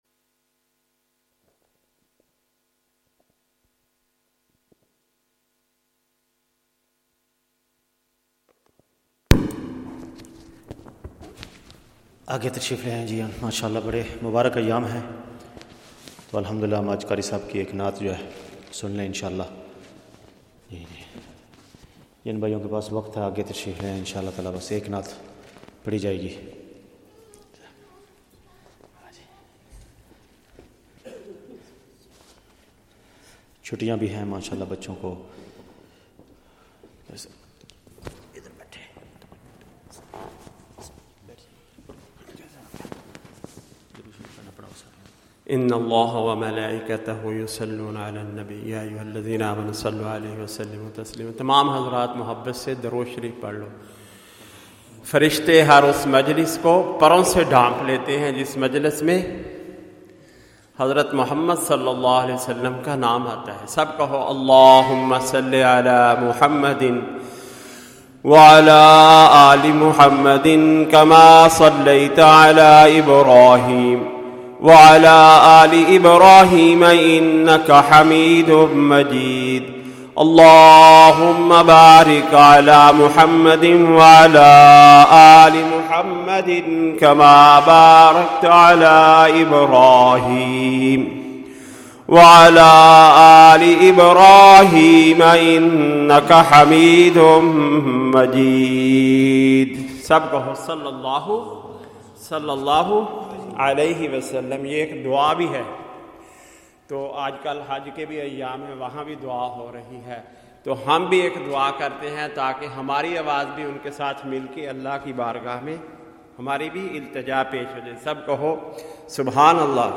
Nasheed Mahfil (Urdu & Punjabi) after Jumah Salah